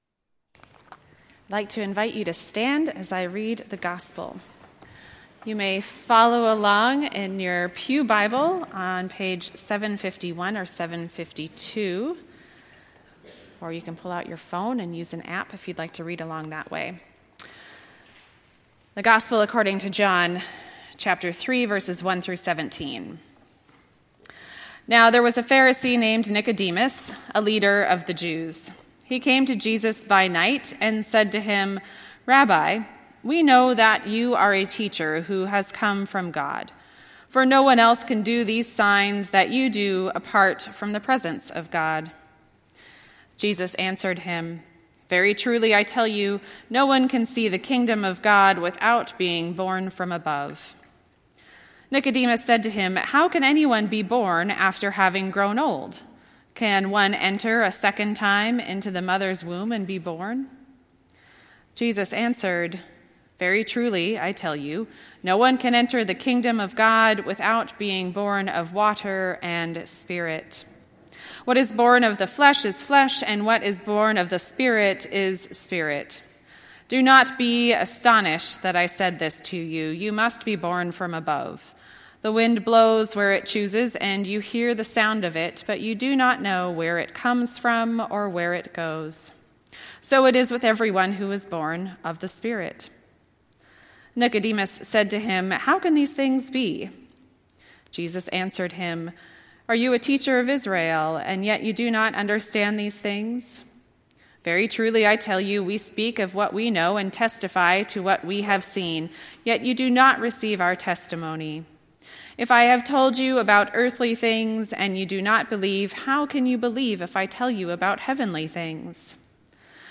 Our Redeemer Lutheran Church Garden Grove Sermons